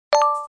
pickup_coin.mp3